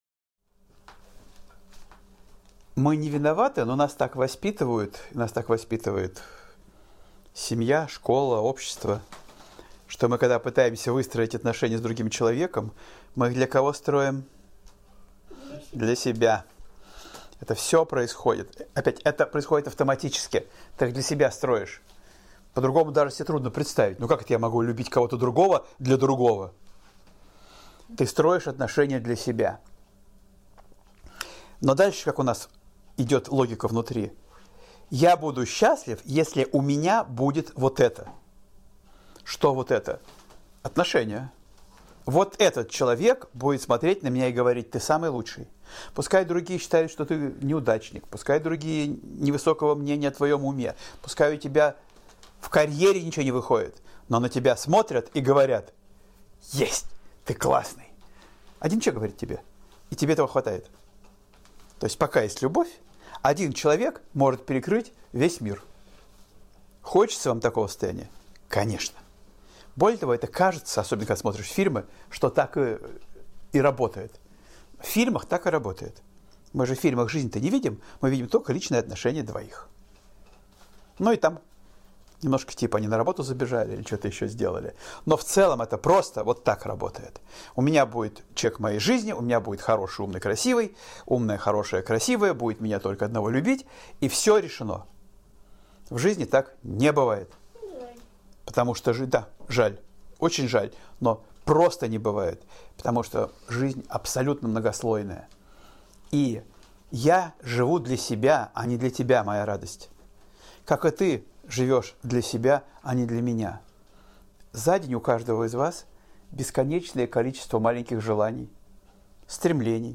Аудиокнига Как строить отношения и не потерять любовь | Библиотека аудиокниг